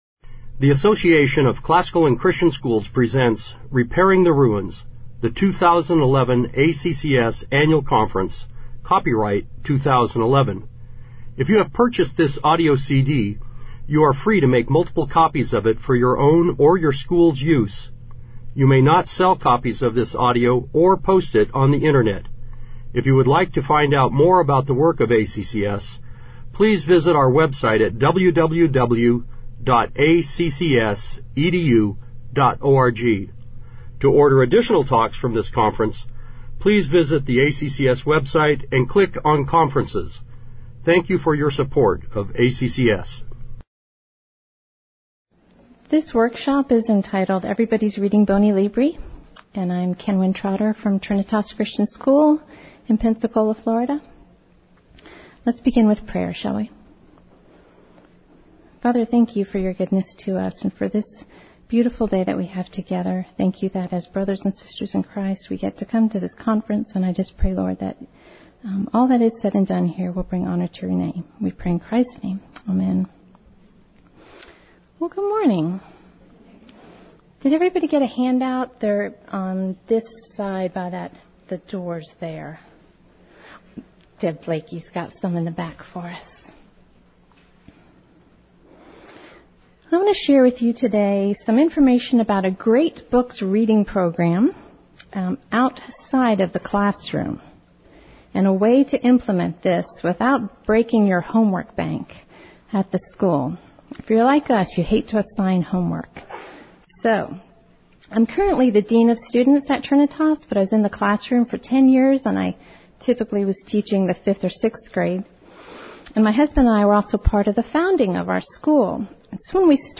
2011 Workshop Talk | 0:54:57 | All Grade Levels, Leadership & Strategic, General Classroom